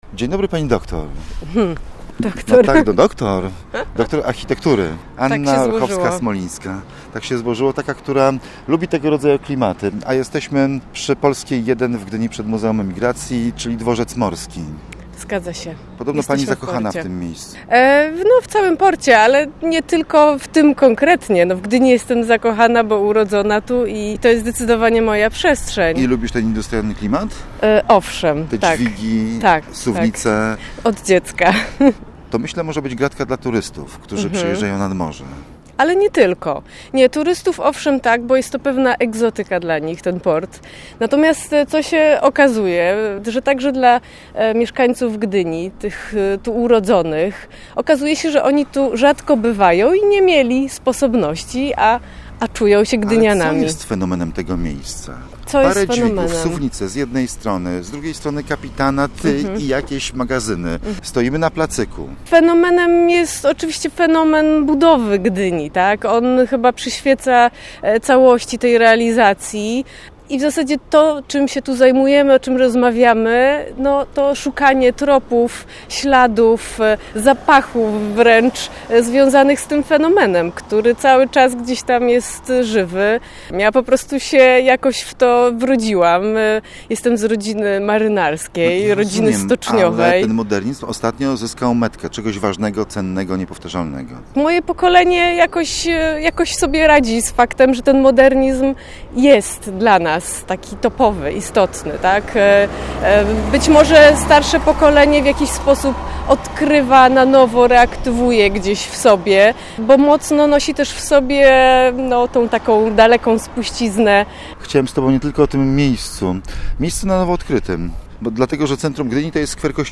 Posłuchaj rozmowy:/audio/dok1/modernizm5.mp3